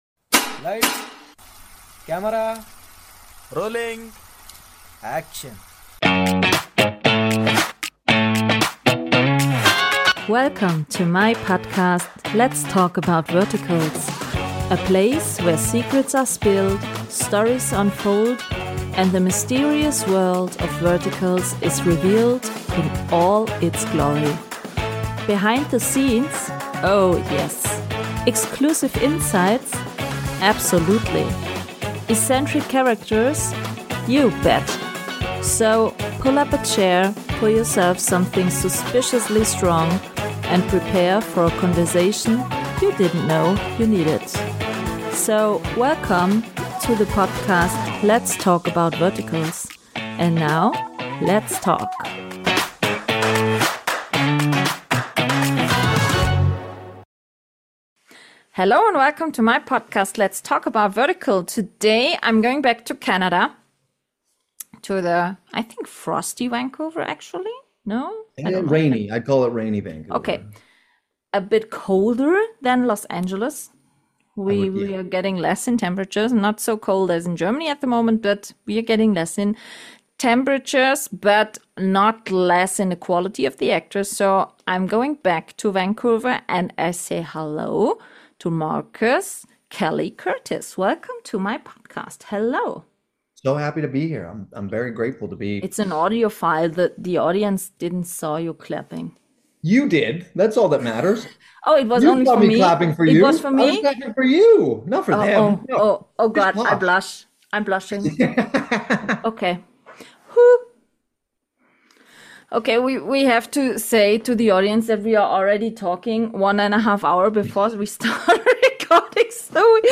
This episode is packed with humor, great stories, and even some deeper, thoughtful moments